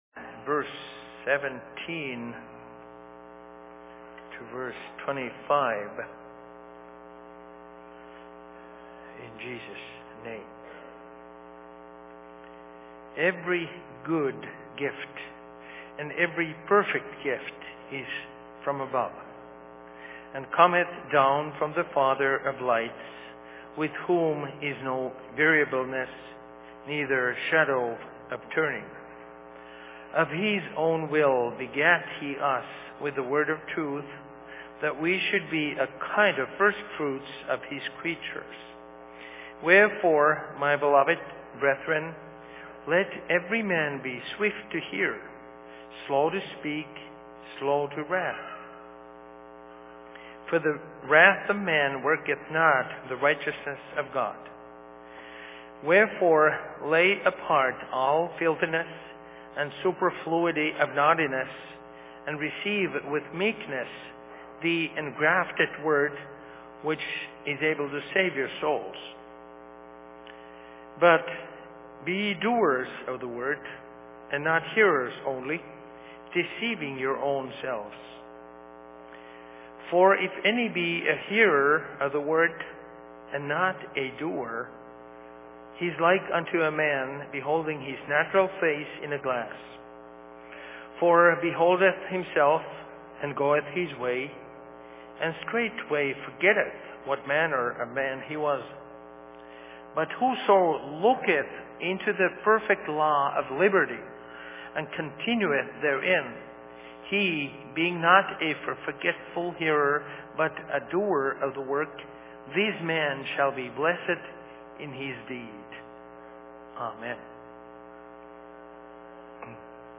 Sermon in Rockford 18.08.2013
Location: LLC Rockford